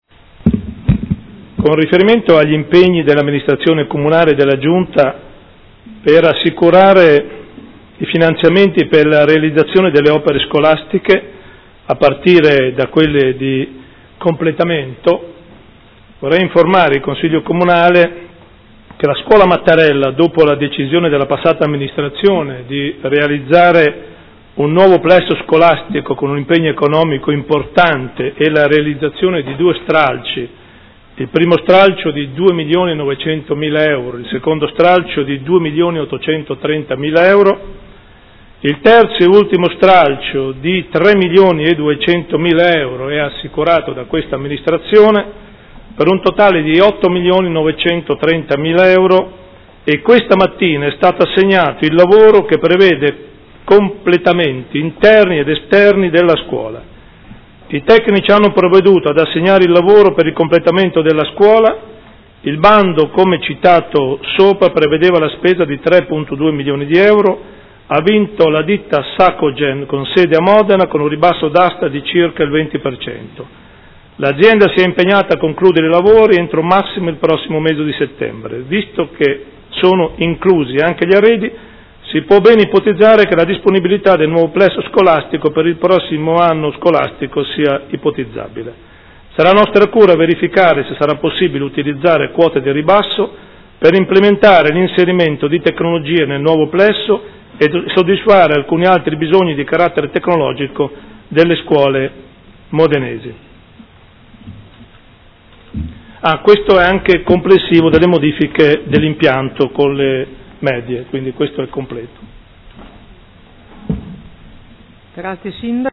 Gian Carlo Muzzarelli — Sito Audio Consiglio Comunale
Seduta del 26/11/2015 Comunicazione su finanziamenti sulle opere scolastiche.